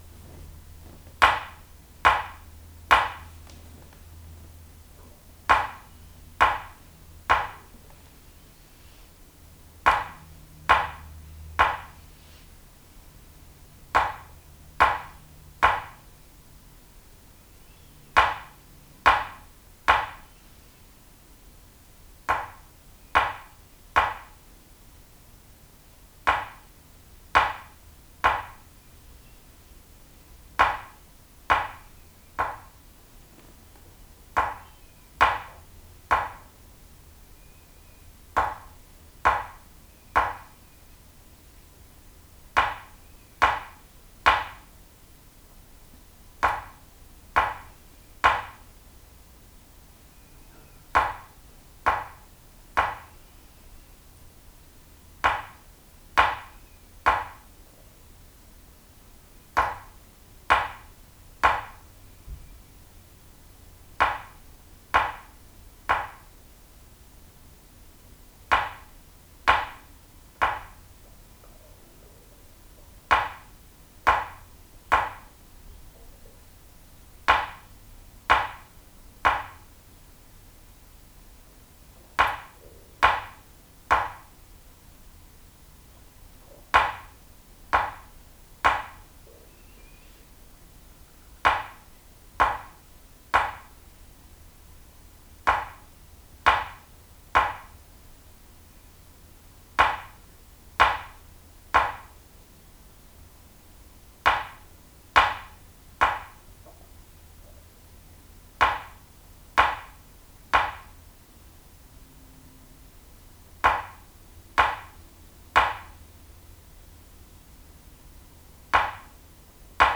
..This "Dead Tapper" will eliminate much of the high overtones which really helps us hear the lowest overtone, which is what we are listening and adjusting our violin parts from...HIGHLY recommended for tap tone tuning!
<<<< CLICK BELOW >>>> photos, to hear (3) Minutes of: {3-Tap; Pause} recordings made with this Lead Shot filled spruce tap tool ....:
Recorded with NCH WavePad Editor and Lead filled spruce tapper.